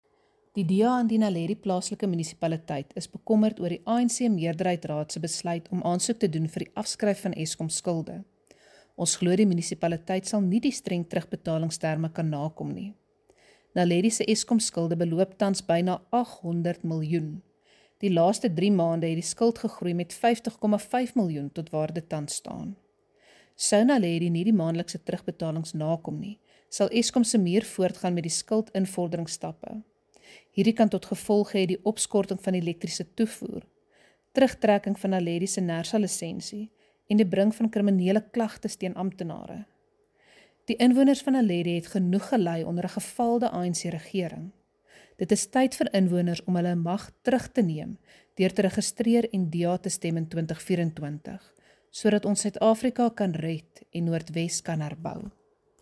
Issued by Cllr Hendriëtte van Huyssteen – DA Caucus Leader: Naledi Local Municipality
Note to Broadcasters: Please find linked soundbites in English and
Afrikaans by Cllr Hendriëtte van Huyssteen.